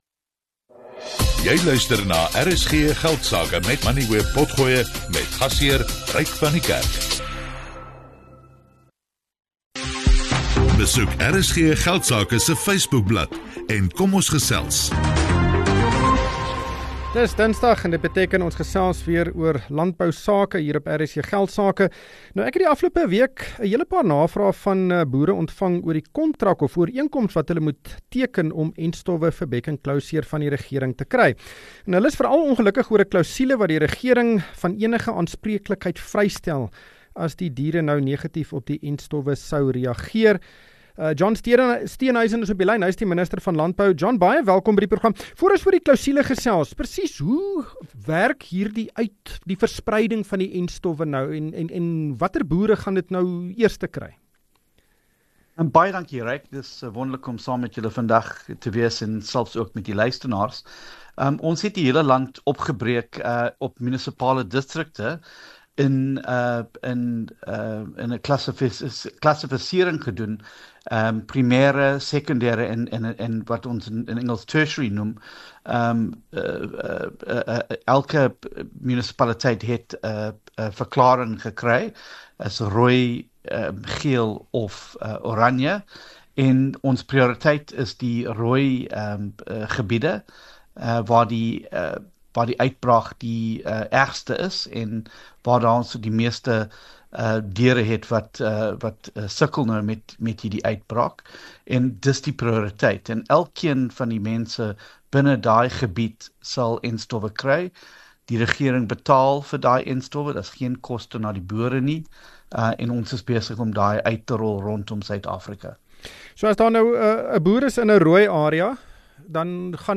John Steenhuisen, minister van landbou, gesels oor die plan om bek-en-klouseer te bekamp, die maatreëls wat ingestel is, en die kwessie van entstof-aanspreeklikheid.